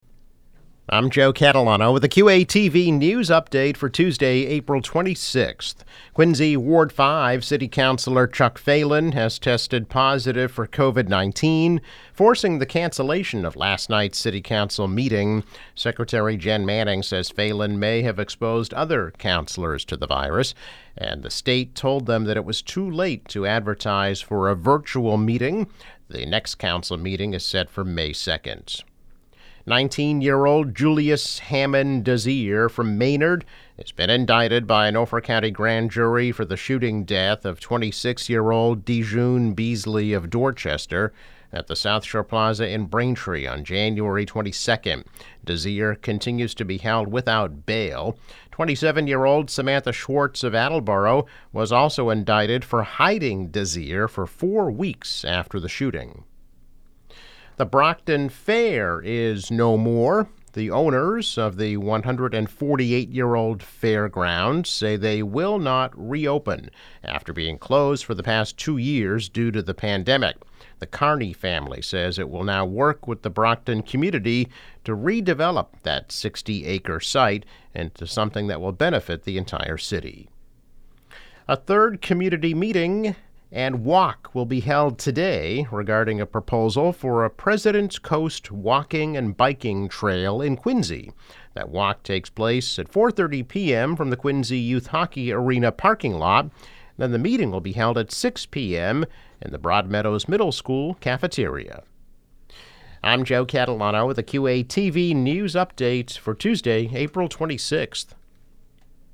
News Update - April 26, 2022